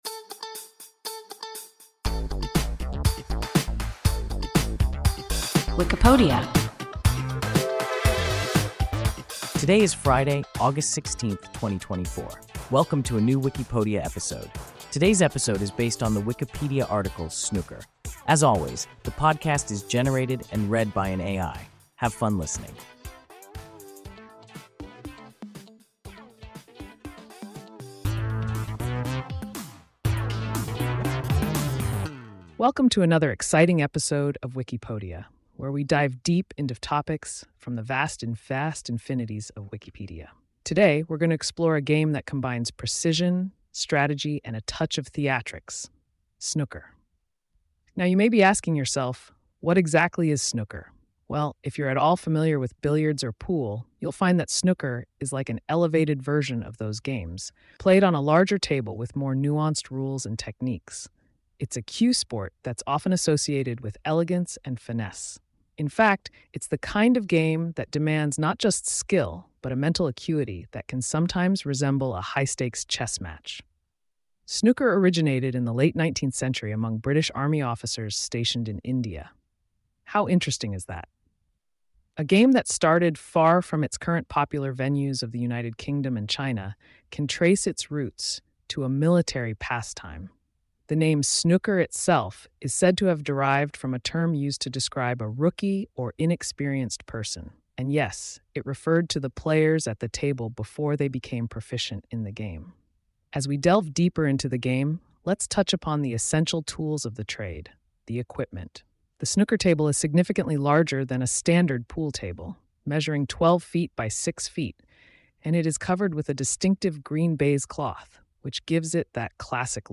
Snooker – WIKIPODIA – ein KI Podcast